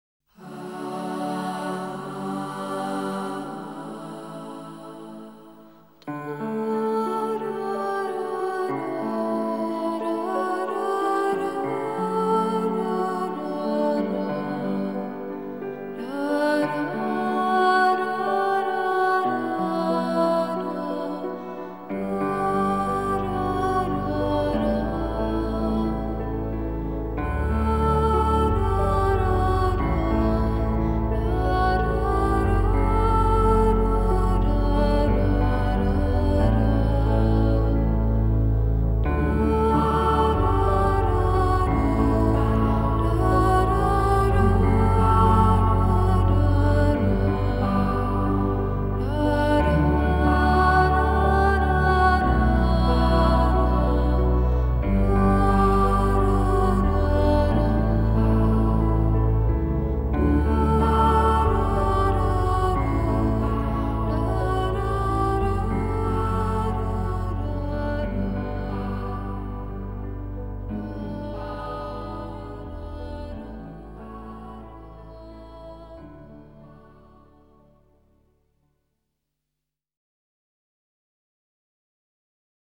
장르: Electronic
스타일: Modern Classical, Minimal, Ambient